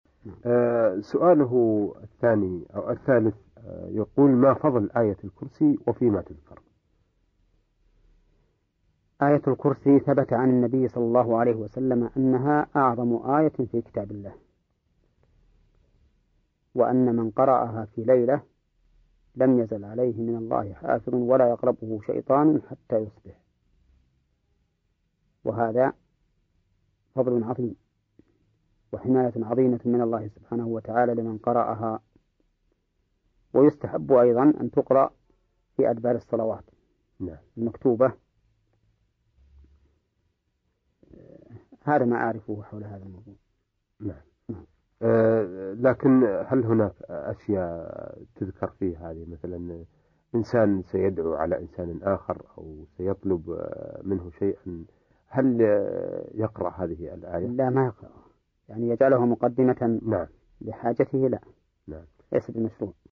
كلمة من برنامج نورن علئ الدرب بعنوان فضل اية الكرسي